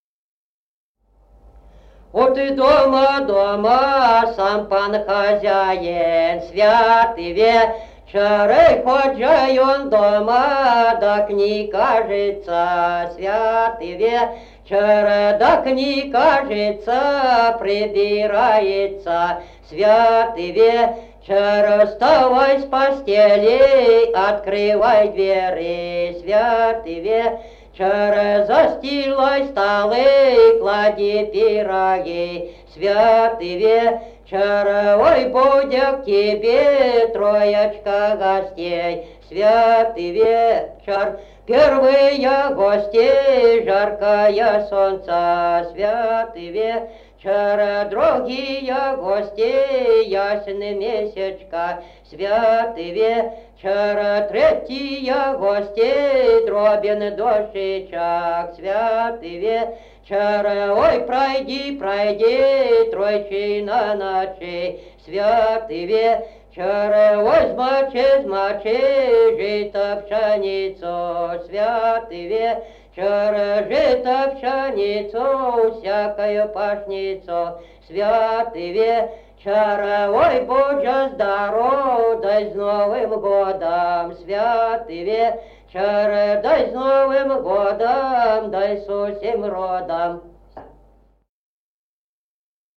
Народные песни Стародубского района «Вот дома, дома», новогодняя щедровная.